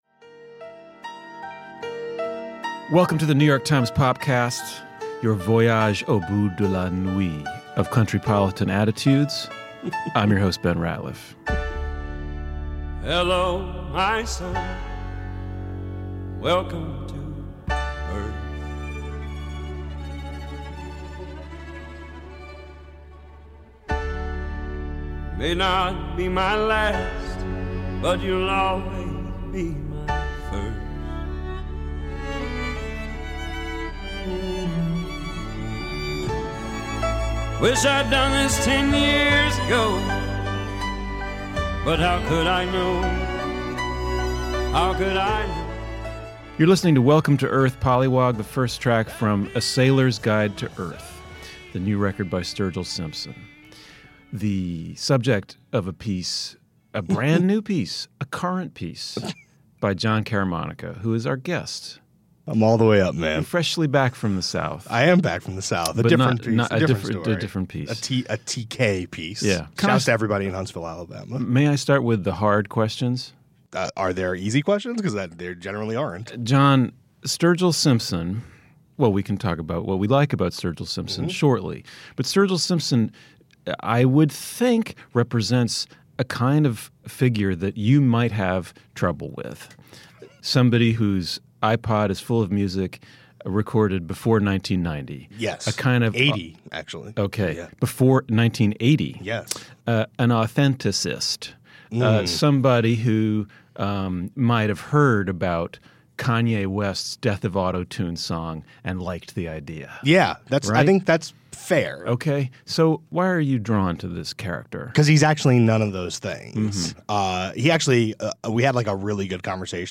New York Times music critics discuss Sturgill Simpson and Merle Haggard.